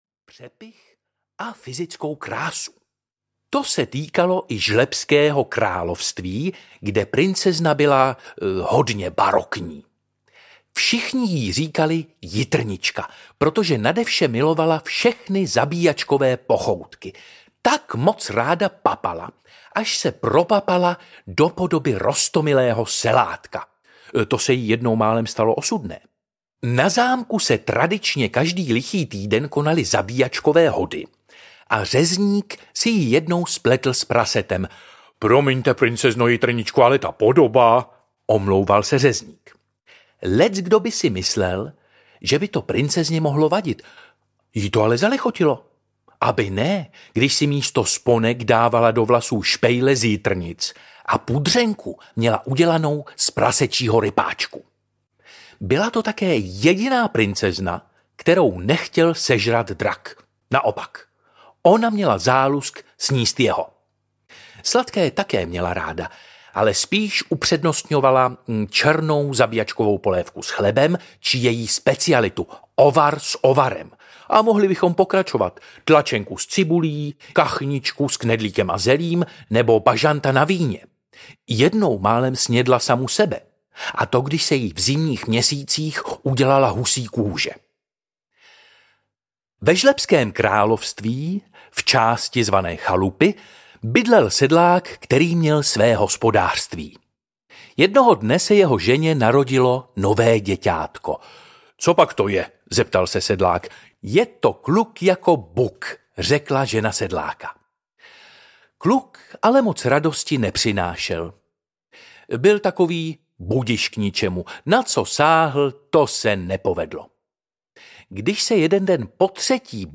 Žlebské pohádky audiokniha